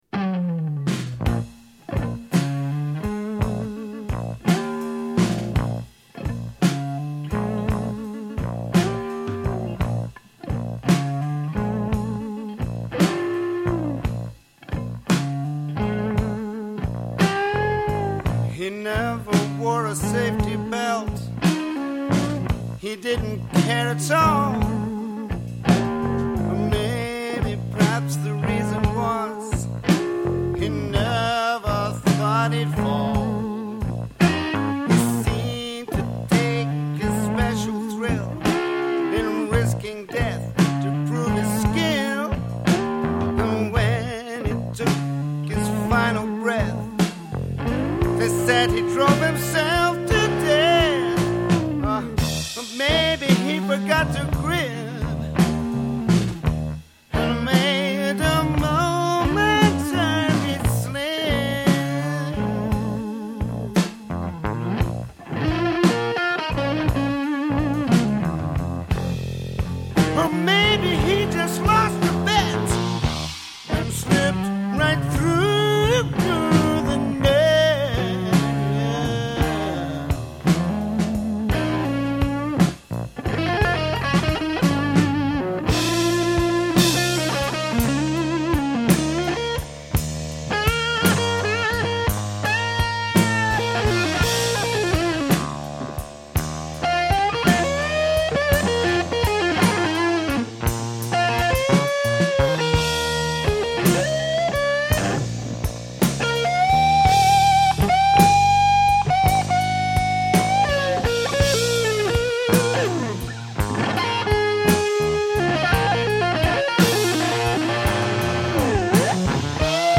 power trio format
the bass is high in the mix
a blues scorcher